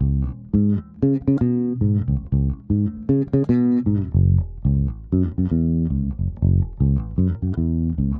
04 Bass PT2.wav